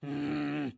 描述：Voz de personaje坟墓，没有领带palabras，独唱儿子sonidos de rabia。